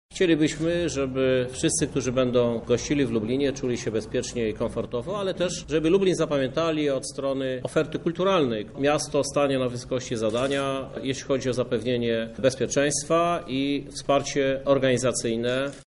Spodziewamy się, że odwiedzi nas nawet kilkanaście tysięcy osób spoza regionu – mówi Krzysztof Żuk, prezydent Lublina.